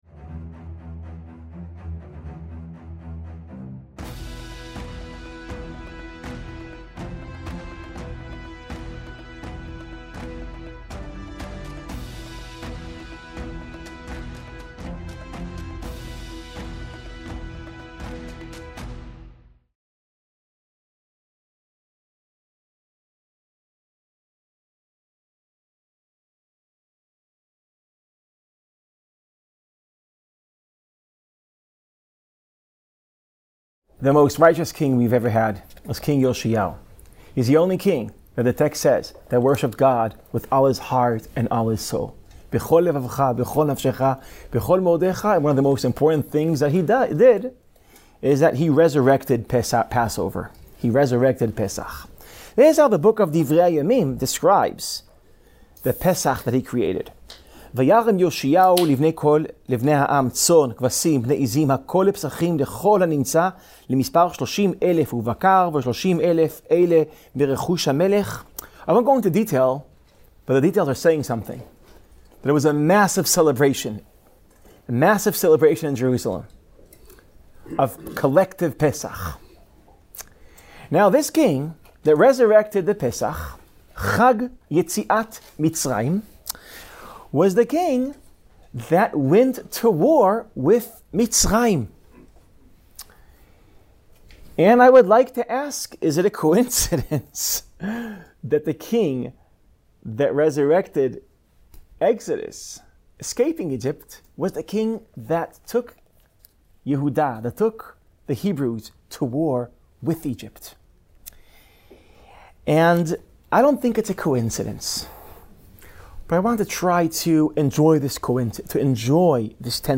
In this concluding lecture